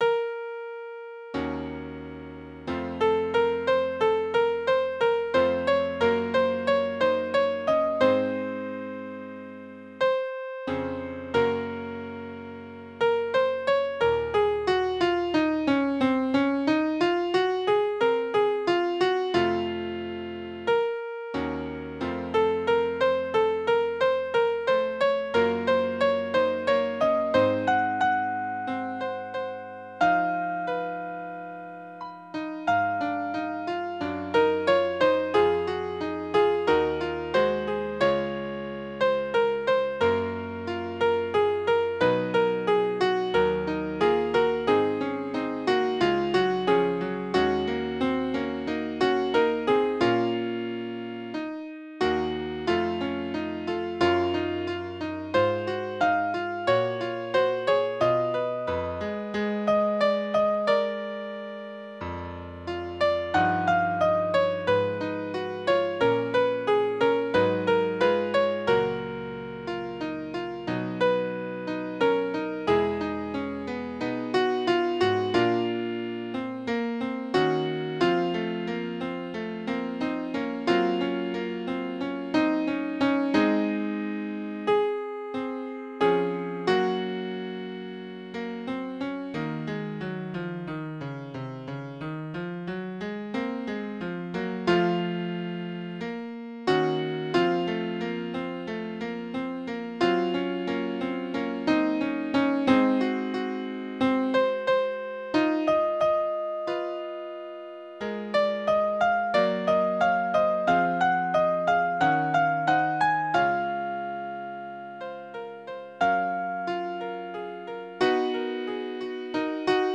AgnusDei_sop2.mp3